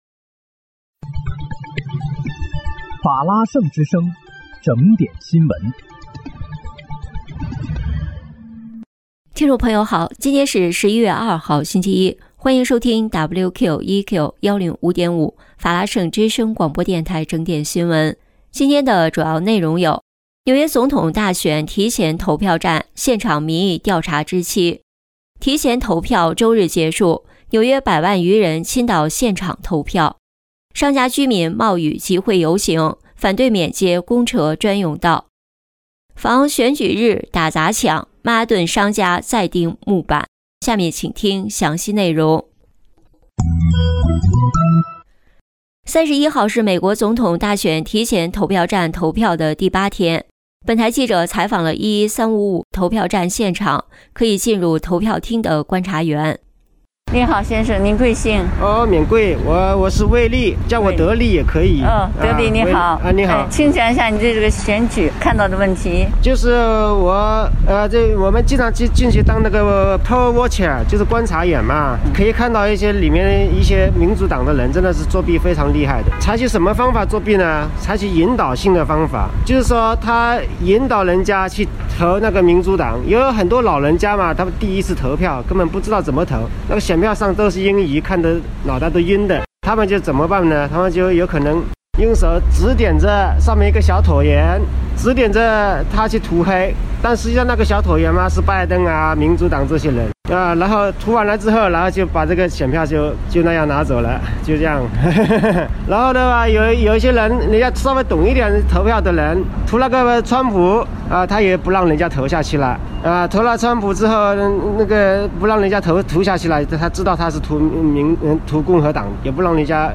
11月2日（星期一）纽约整点新闻
欢迎收听WQEQ105.5法拉盛之声广播电台整点新闻。